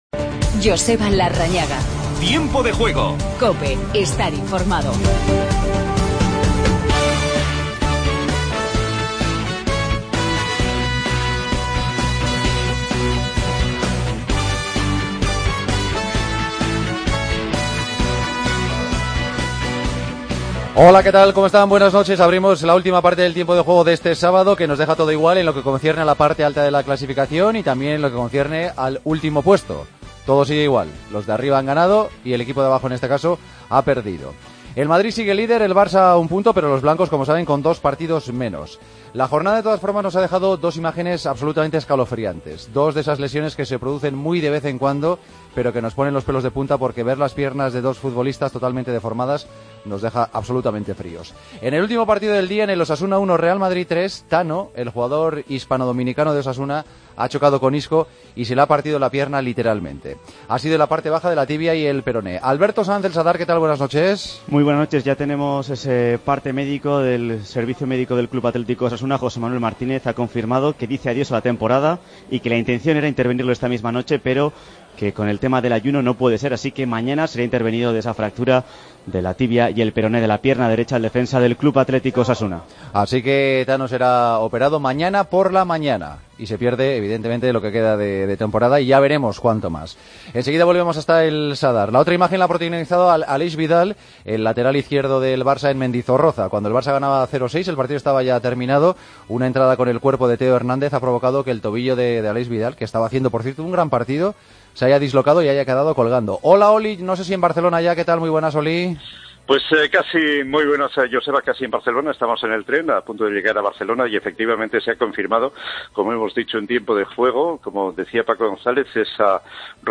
Escuchamos a Sergio Ramos y a Keylor Navas. Hablamos con Sergio León. Además, victoria del Athletic frente al Deportivo y empate entre Betis y Valencia.